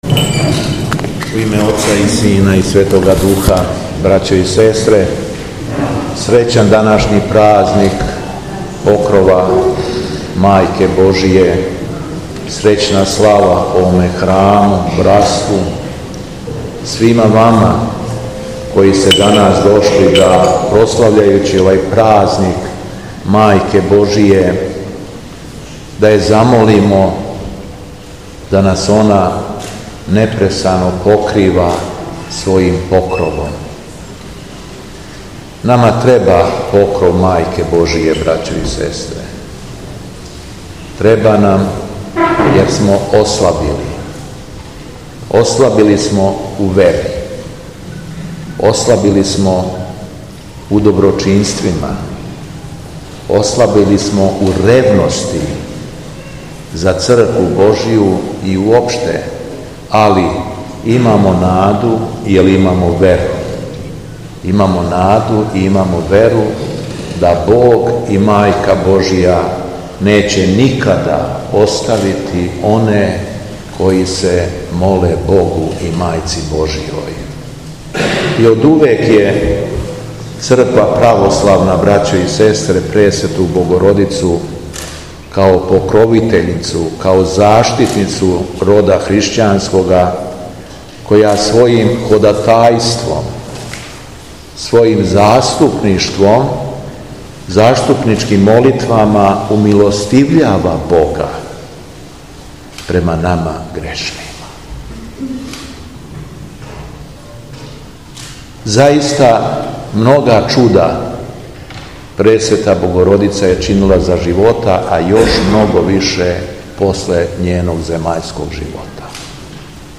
У суботу 1/14. октобра 2023. године Његово Преосвештенство Епископ шумадијски Господин Јован посетио је Јагодину и служио Свету архијерејску Литургију у цркви Покрова Пресвете Богородице поводом славе ове градске богомоље.
Беседа Његовог Преосвештенства Епископа шумадијског г. Јована
Епископ је у проповеди говорио врло надахнуто: